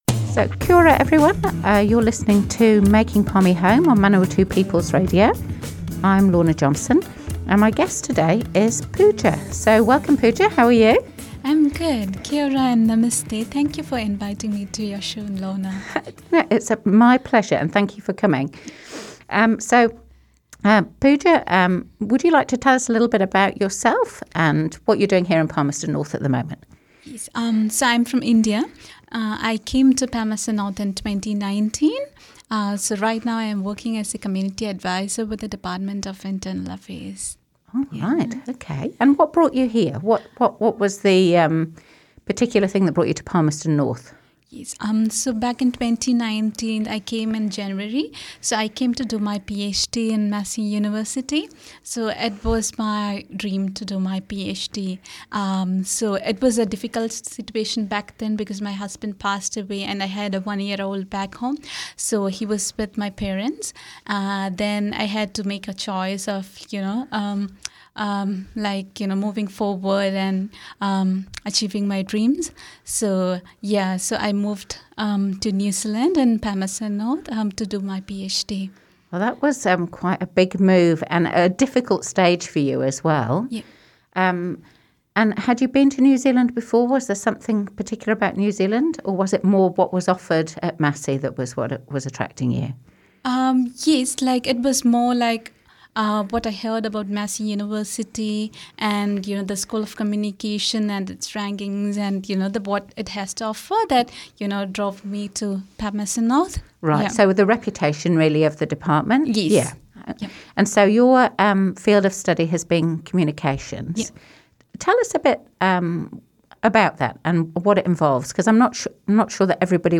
Palmerston North City Councillor Lorna Johnson hosts "Making Palmy Home", a Manawat? People's Radio series interviewing migrants about their journeys to Palmerston North.